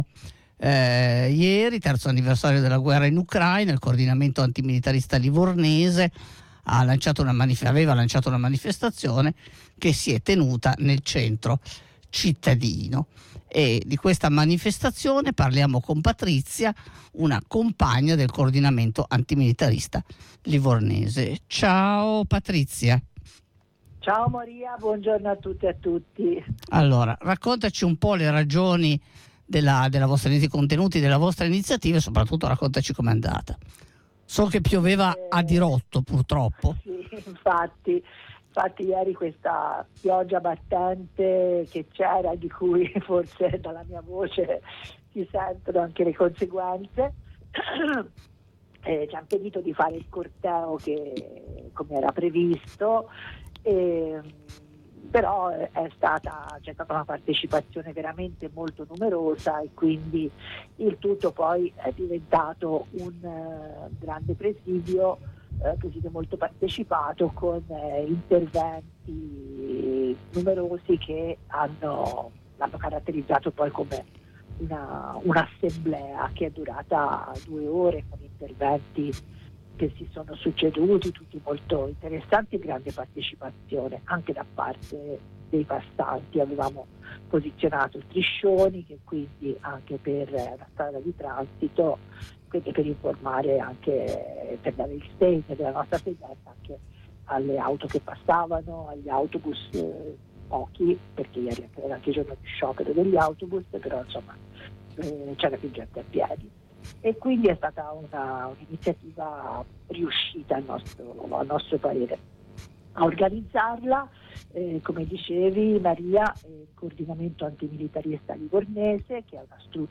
Ascolta qui le cronache delle iniziative di Livorno e Torino: